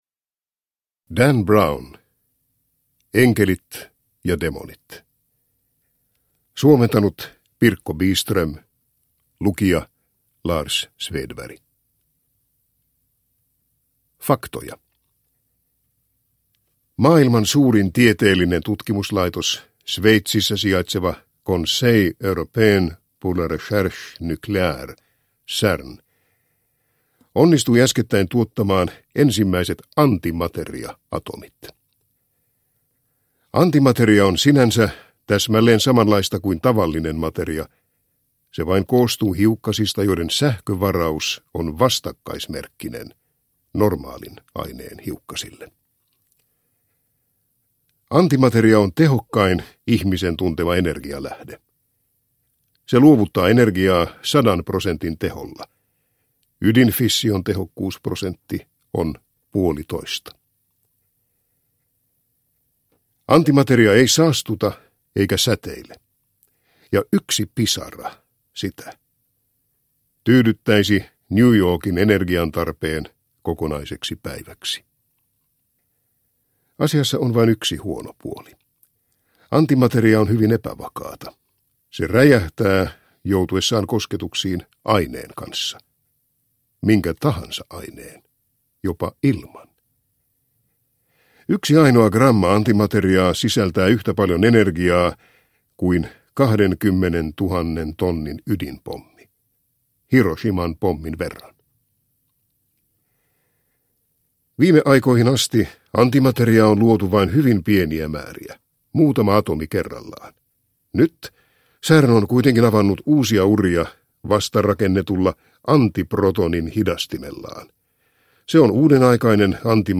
Enkelit ja demonit – Ljudbok – Laddas ner